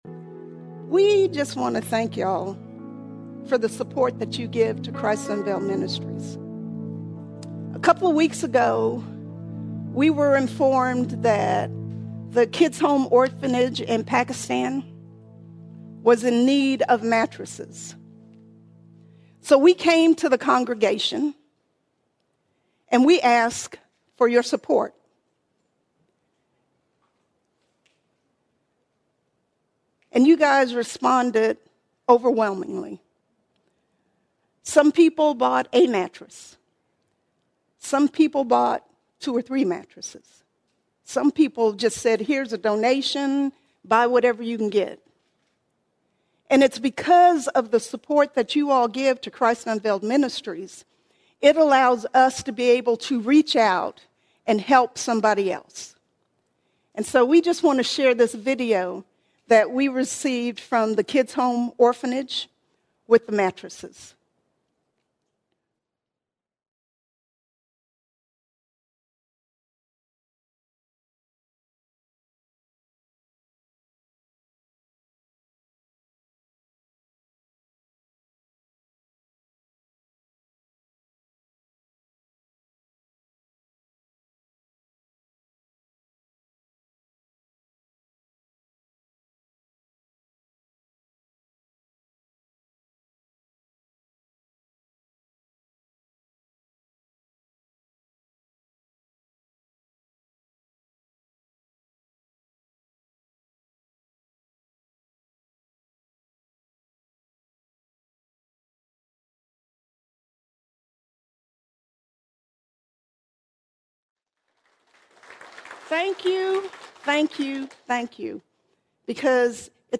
16 February 2026 Series: Sunday Sermons All Sermons Reality Check Reality Check Jesus is not just real—He is the greatest reality known to man.